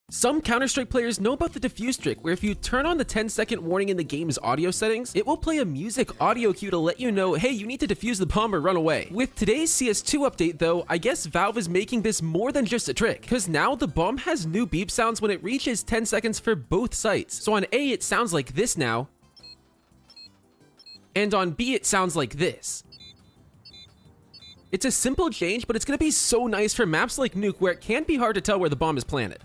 new cs2 10 second bomb sound effects free download
new cs2 10 second bomb sounds!!